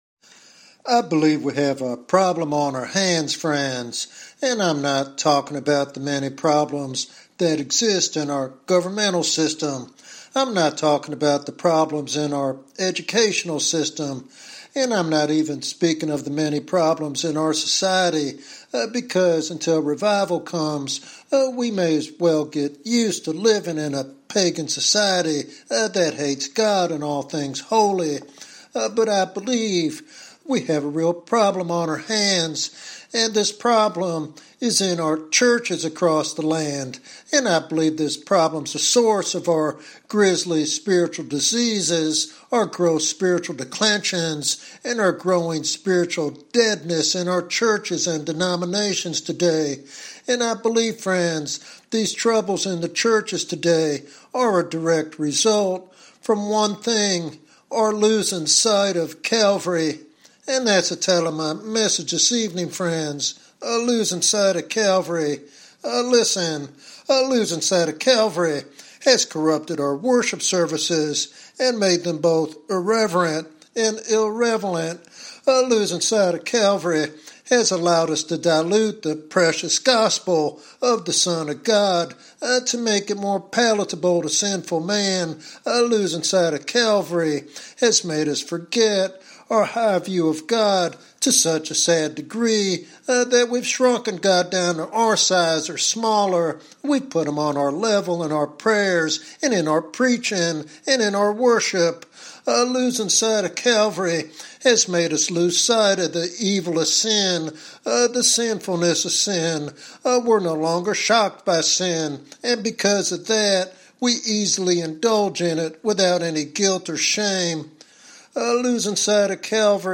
This sermon is a stirring invitation to rediscover the power and wonder of Calvary as the foundation for revival and holy living.